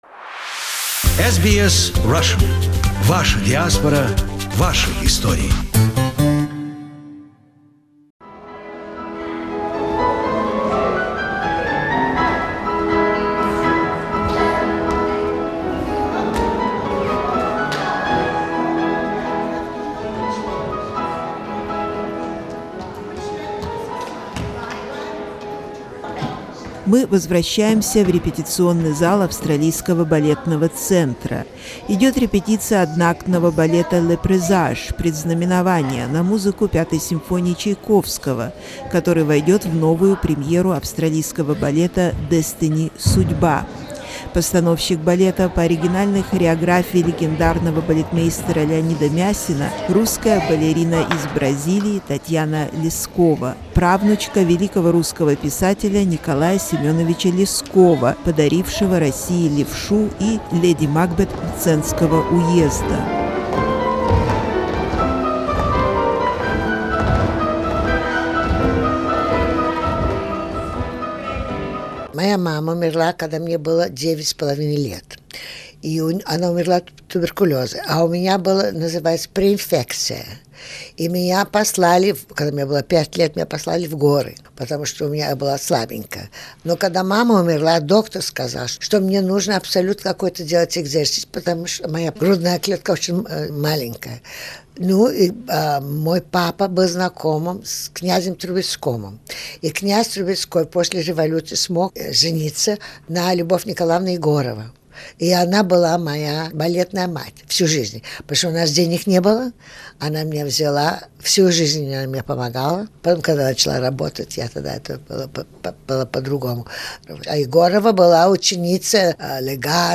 We spoke with her in 2007 at the rehearsal when she was invited to help restore Leonide Massine first symphonic ballet, Les Presages for Australia Ballet production. The legendary ballerina spoke of her upbringing by the Russian emigre parents in Paris and studies with Lubov Egorova .